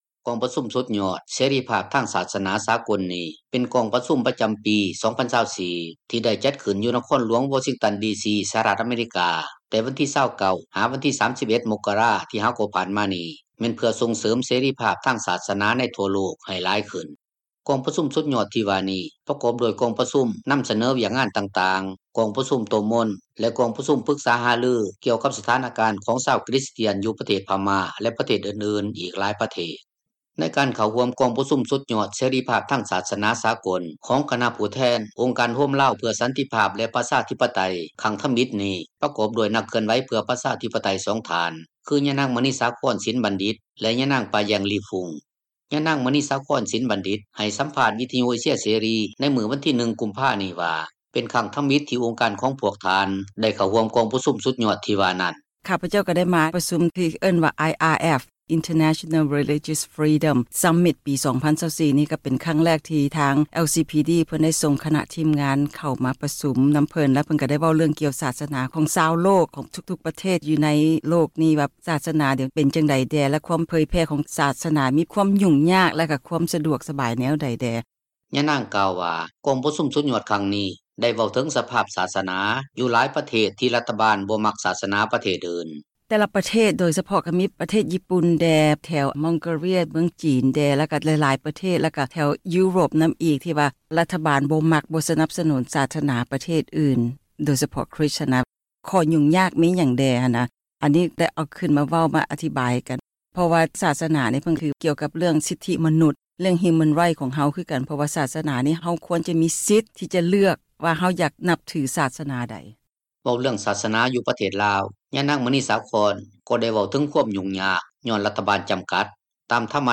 ກອງປະຊຸມ ສຸດຍອດເສຣີພາບ ທາງສາສນາສາກົລ ປະຈຳປີ 2024 ຄະນະຜູ້ແທນອົງການ ໂຮມລາວເພື່ອສັນຕິພາບ ແລະປະຊາທິປະໄຕ ທີ່ເຂົ້າຮ່ວມກອງປະຊຸມ ສຸດຍອດເສຣີພາບ ທາງສາສນາສາກົລ ທີ່ນະຄອນຫຼວງ ວໍຊິງຕັນ ດີ.ຊີ. ໃຫ້ສັມພາດ ກັບນັກຂ່າວ ວິທຍຸ ເອເຊັຽ ເສຣີ ວັນທີ 1 ກຸມພາ 2024.